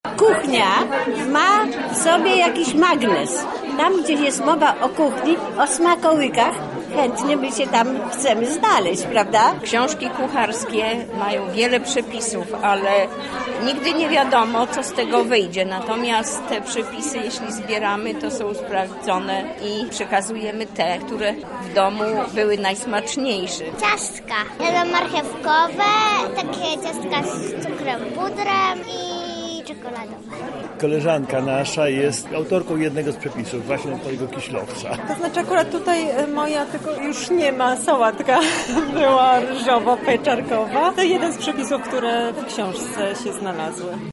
Za nami premiera książki kulinarnej – „Smaki Węglina”.